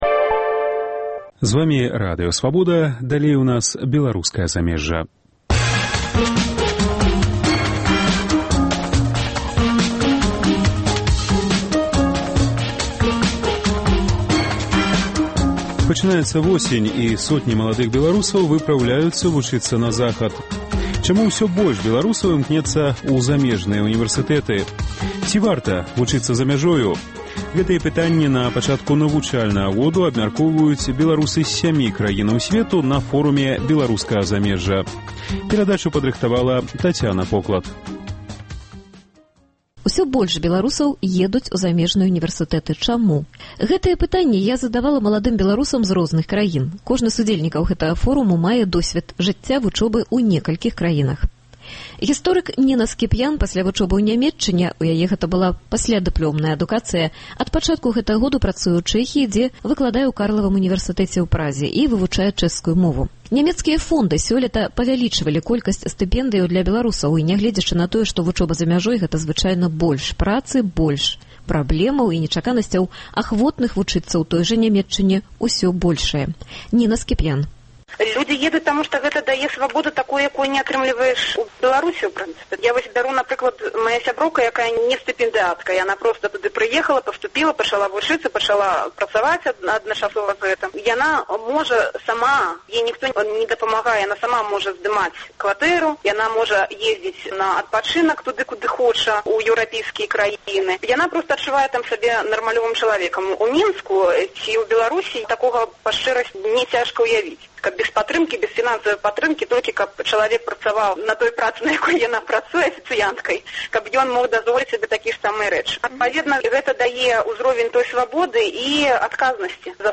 Ці варта вучыцца за мяжой – гэтае пытаньне напачатку навучальнага году абмяркоўваюць беларусы з сямі краін сьвету на Форуме Беларускага замежжа.